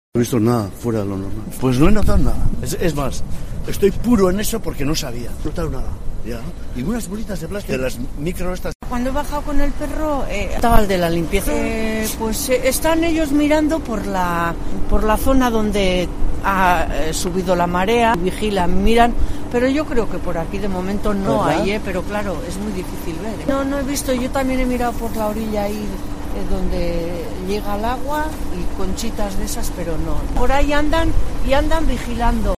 AUDIO: Paseantes de la playa de la Zurriola en San Sebastián a los que hemos preguntado sobre los pélets